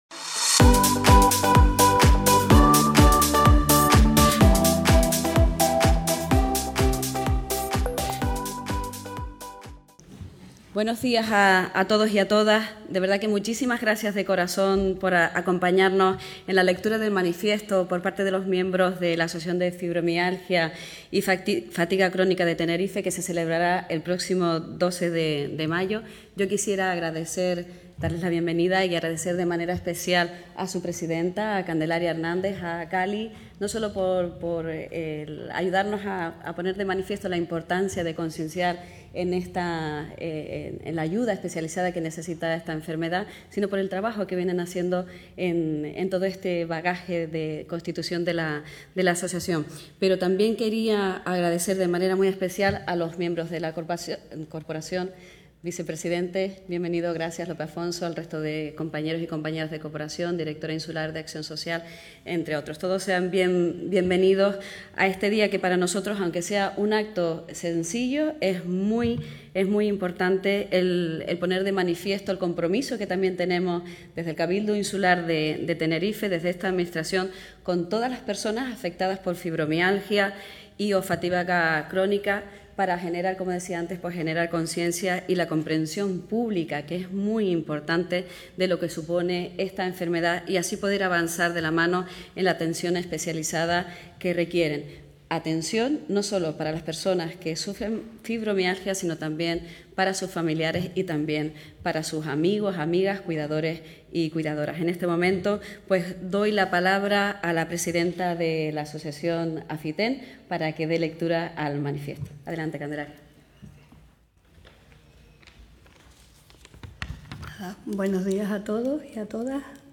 El Salón Noble del Cabildo de Tenerife acogió hoy (jueves 9) la lectura de un manifiesto con motivo del Día Mundial de la Fibromialgia, que se conmemora el próximo domingo, 12 de mayo.